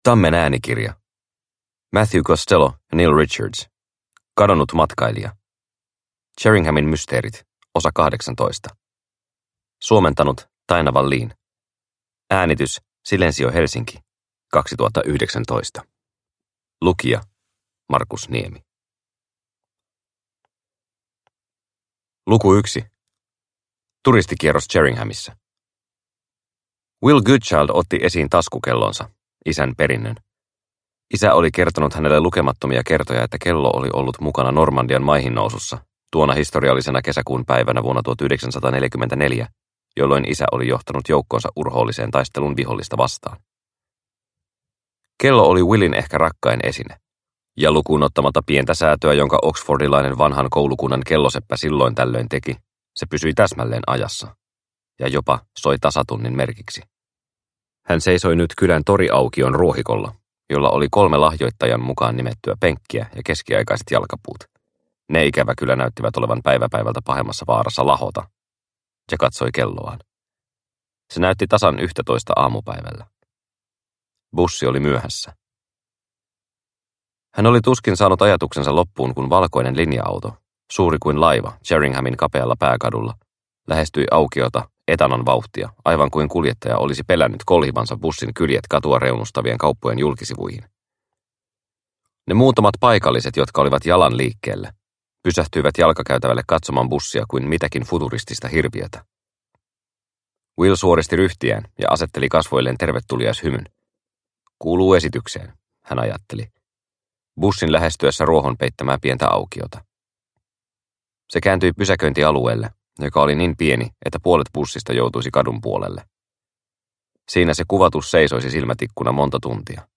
Kadonnut matkailija – Ljudbok – Laddas ner